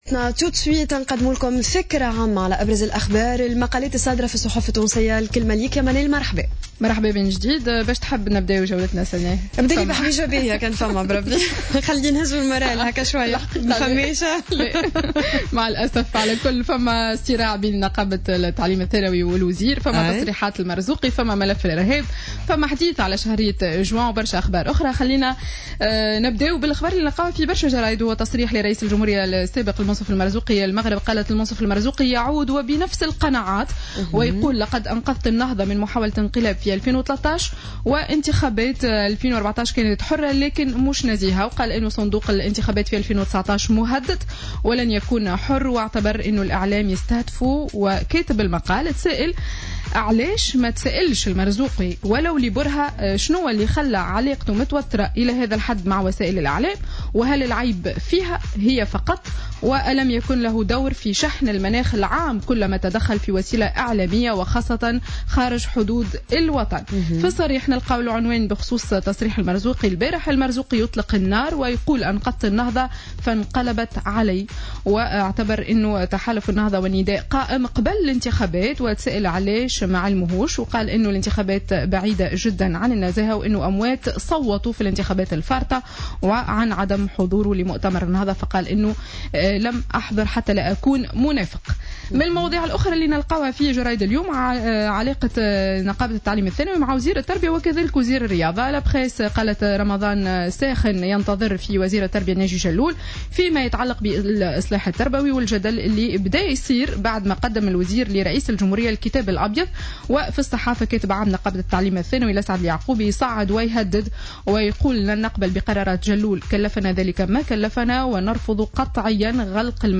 Revue de presse du samedi 28 Mai 2016